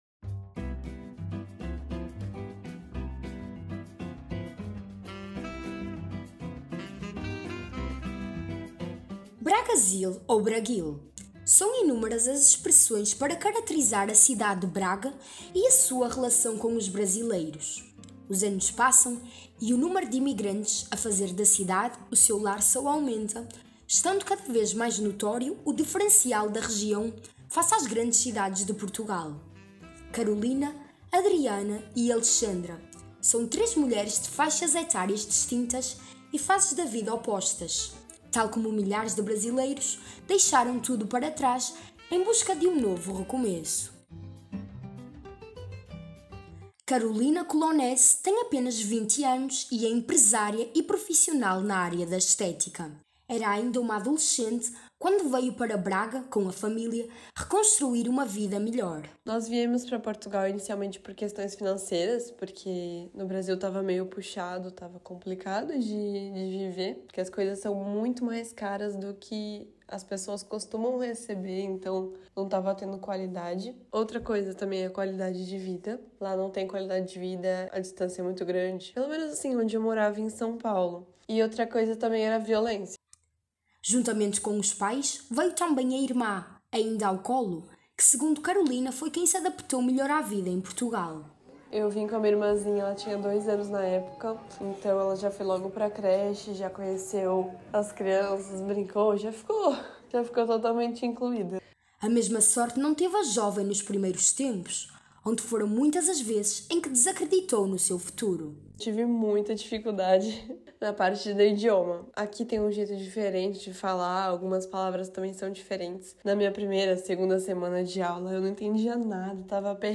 Reportagem Multimédia "Shoppings Fantasma" Reportagem Radiofónica Zerar a vida.